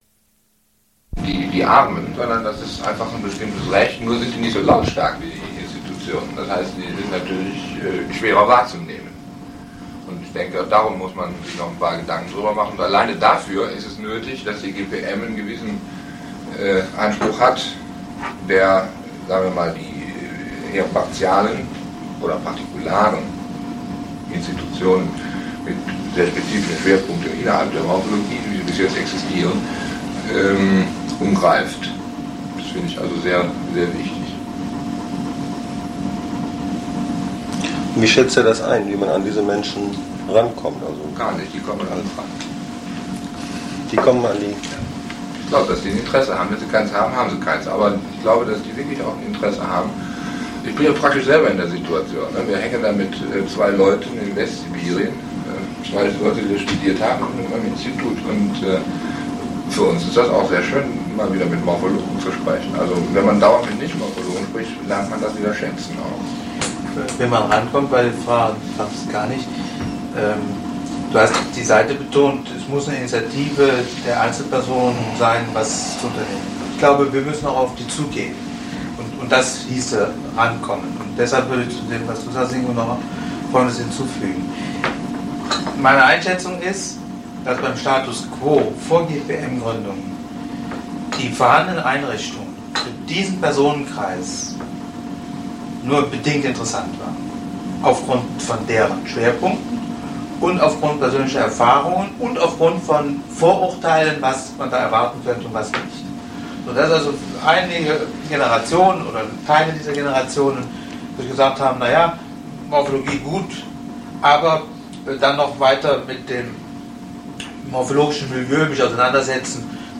ZS-Gespräch mit der GPM 2